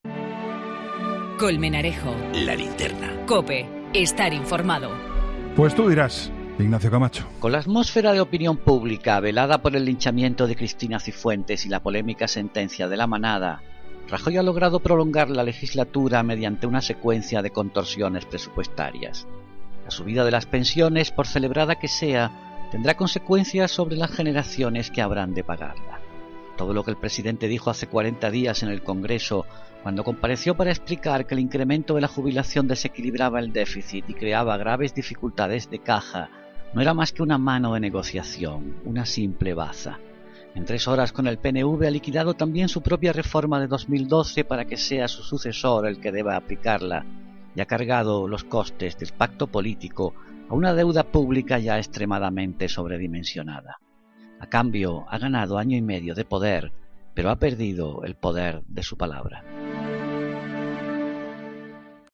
Ignacio Camacho habla en 'La Linterna' del apoyo del PNV al Gobierno para rechazar las enmiendas a la totalidad de los presupuestos
Este jueves, tras presentar los presupuestos en el Congreso, Ignacio Camacho comenta en 'La Linterna' cómo han conseguido rechazar las enmiendas a la totalidad gracias al apoyo del PNV: